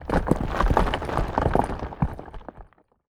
rock_smashable_falling_debris_03.wav